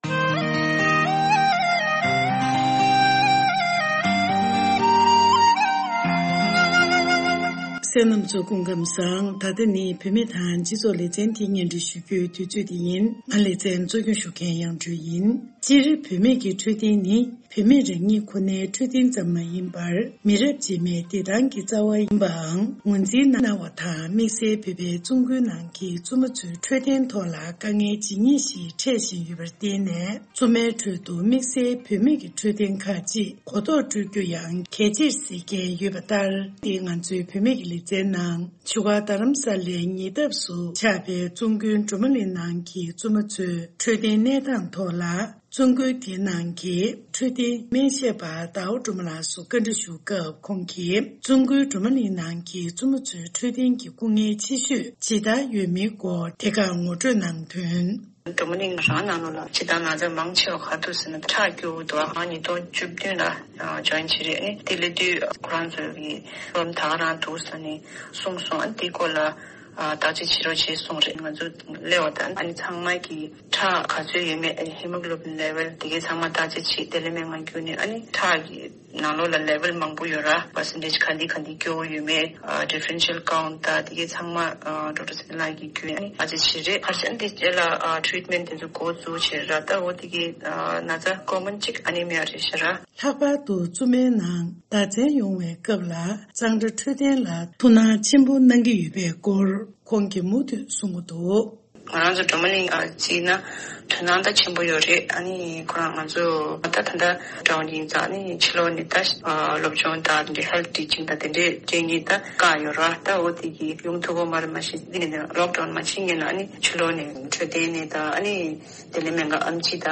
འབྲེལ་ཡོད་མི་སྣར་གནས་འདྲི་ཞུས་སྟེ་གནས་ཚུལ་ཕྱོགས་སྒྲིག་ཞུས་པ་ཞིག་གསན་རོགས་གནང་།